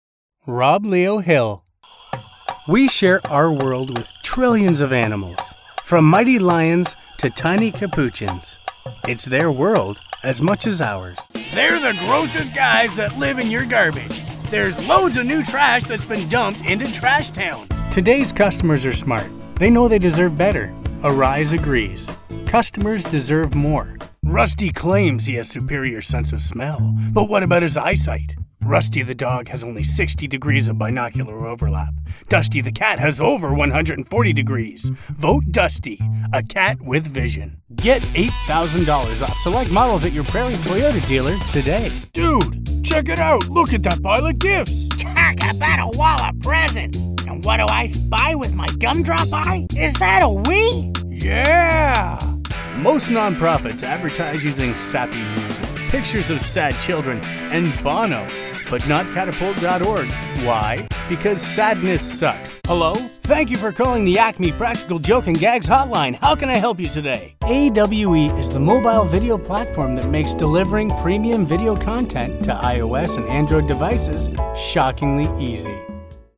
Guy Next Door, Young, Energetic and Natural.
Sprechprobe: eLearning (Muttersprache):
My home studio allows me to offer my clients profession recordings quickly and efficiently.